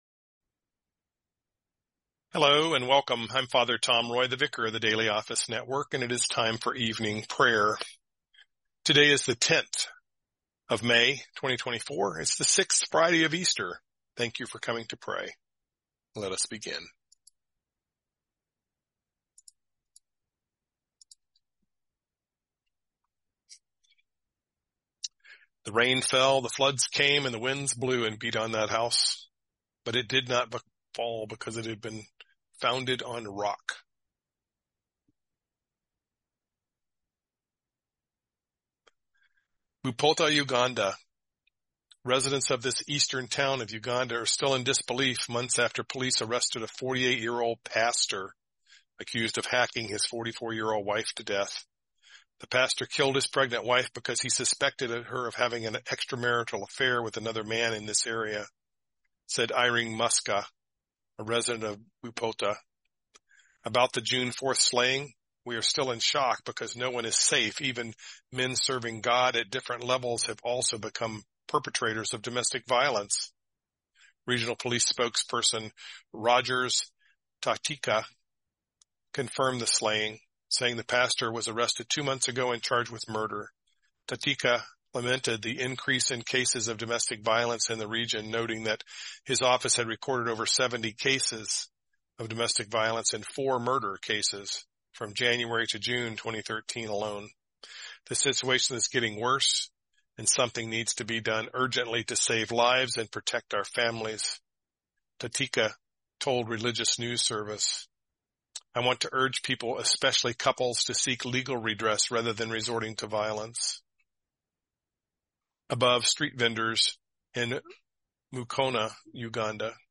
Evening Prayer